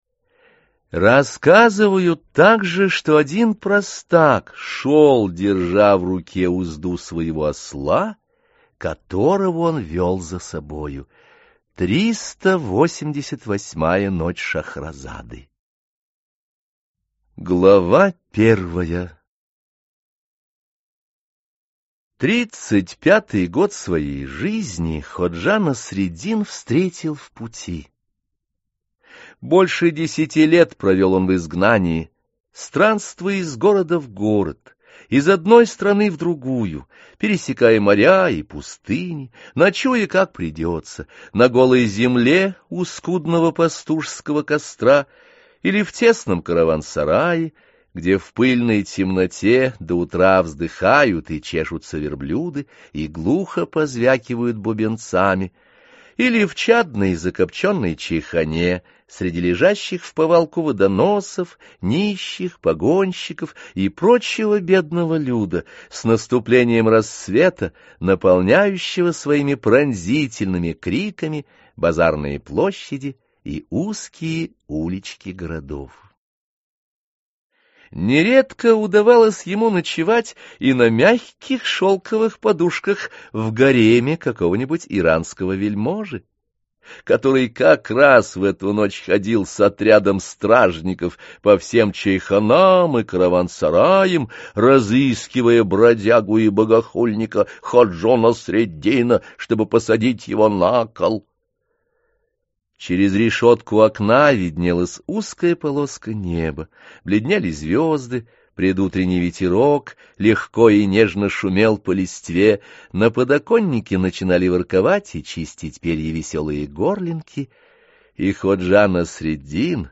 Аудиокнига Возмутитель спокойствия | Библиотека аудиокниг